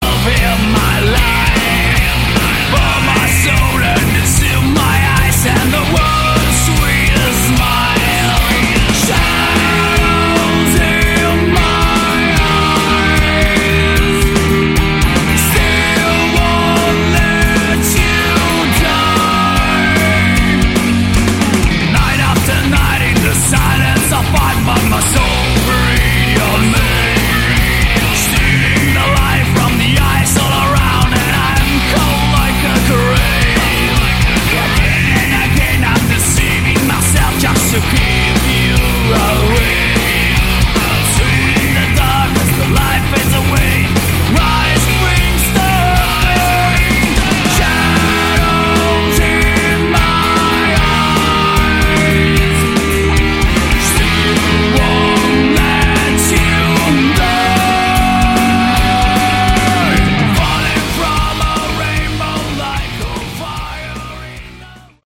Category: Hard Rock
lead vocals
bass, backing vocals
guitar
drums